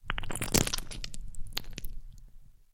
Звуки томата: хруст раздавленного помидора